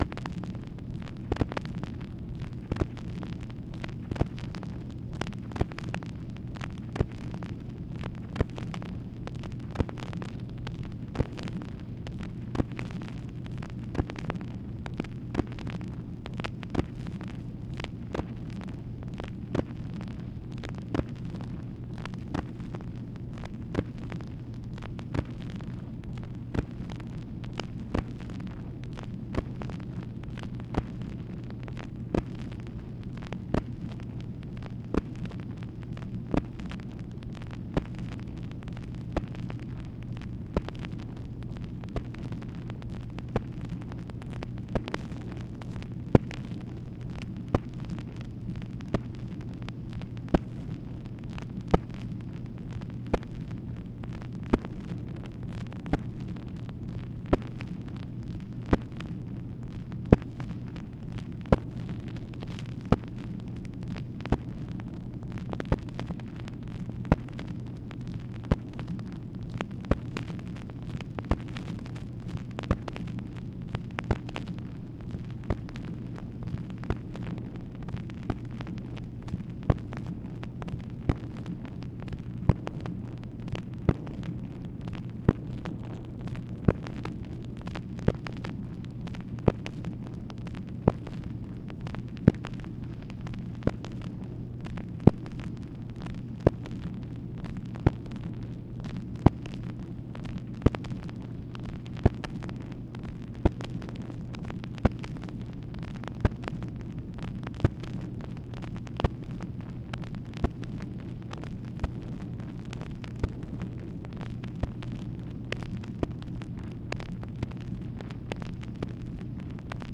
MACHINE NOISE, August 5, 1964
Secret White House Tapes | Lyndon B. Johnson Presidency